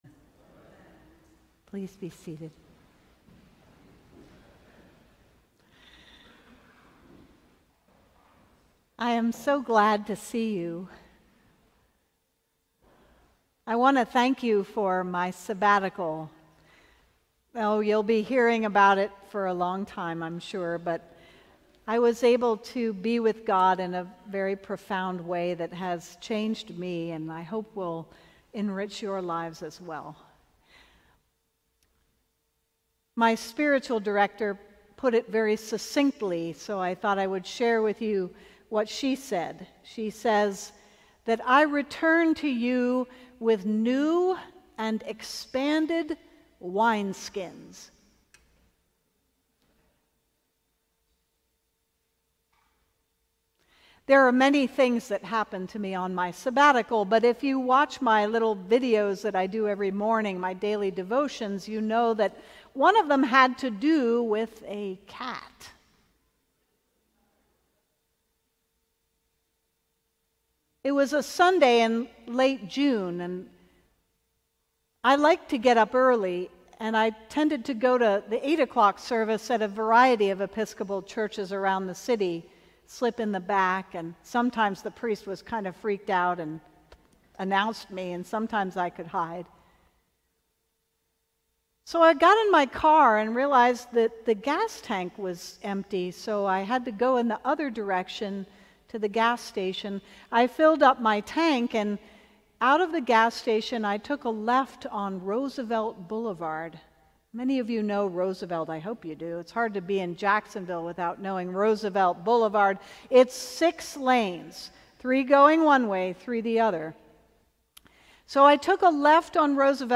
Sermon: A Heavenly Suitcase - St. John's Cathedral